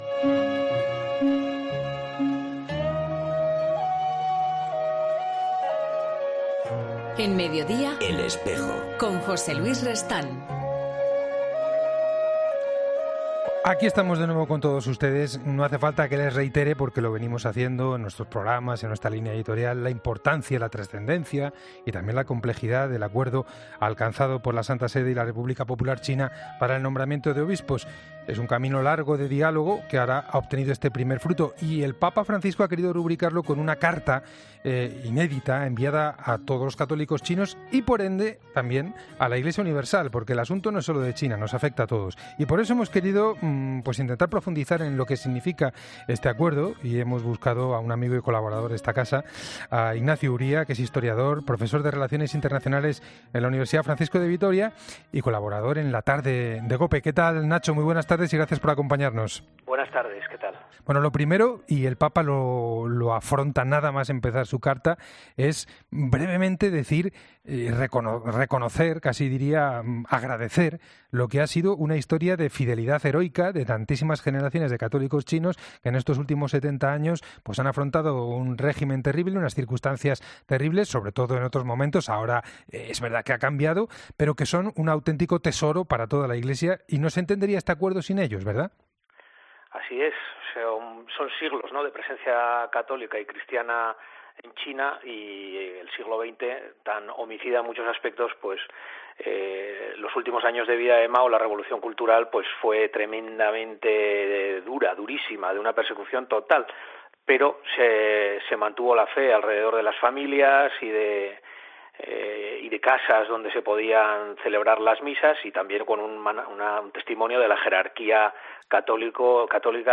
"Algo que en China no se puede hacer abiertamente", ha afirmado el entrevistado, quien ha destacado también el papel de los miles de católicos que "especialmente en los últimos años de la vida de Mao, durante la revolución cultural, soportaron condiciones durísimas de persecución, así como el testimonio edificante de la jerarquía".